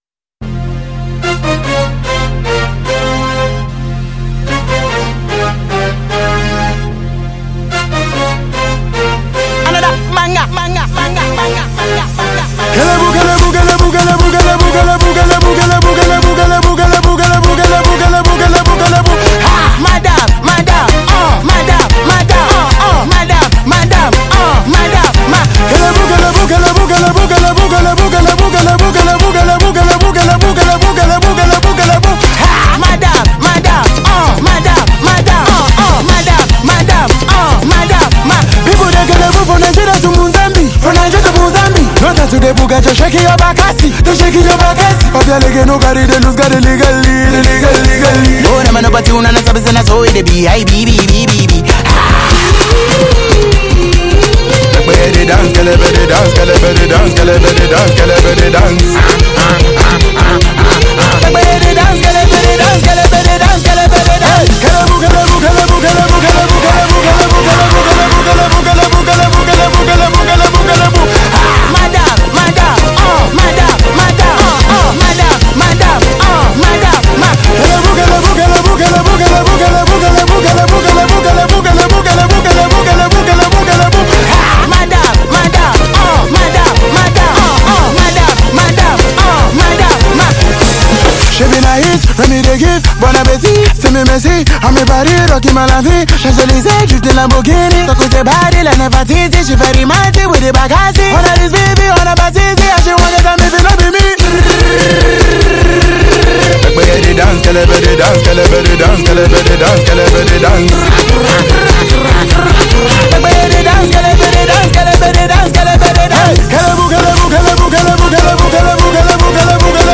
a track that blends Afrobeat vibes with fresh energy.
smooth melodies with vibrant Afrobeat rhythms